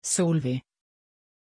Pronunciation of Solvey
pronunciation-solvey-sv.mp3